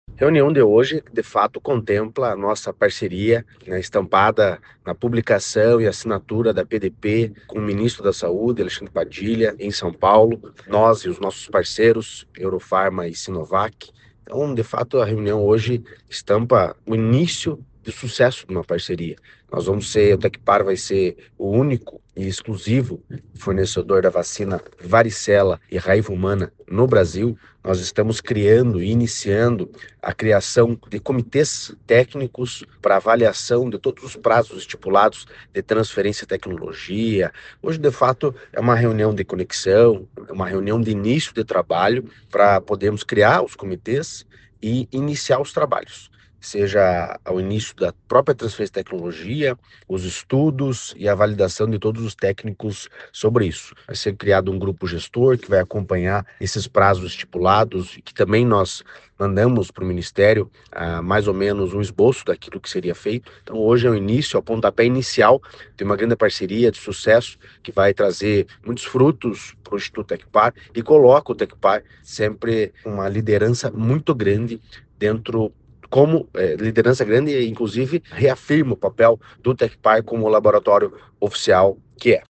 Sonora do diretor-presidente do Tecpar, Eduardo Marafon, sobre a parceria com a Sinovac para produção de vacinas para o SUS no Paraná